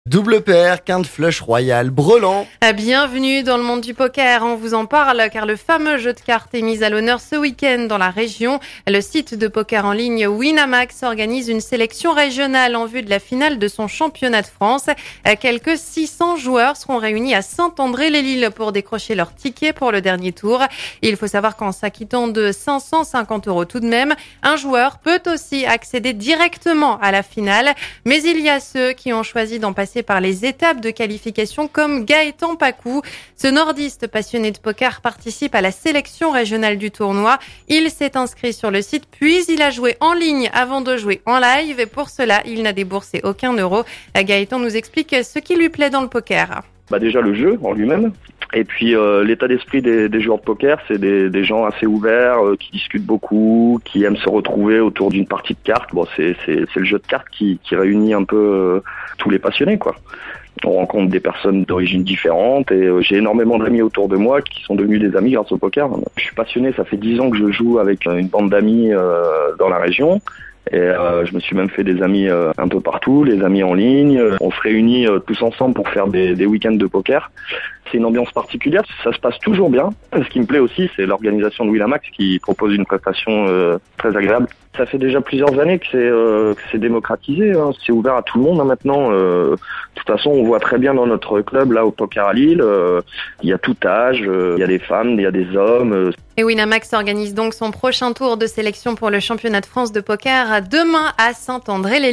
Une interview à la radio :
extrait du journal MONA FM du vendredi 18 novembre 2016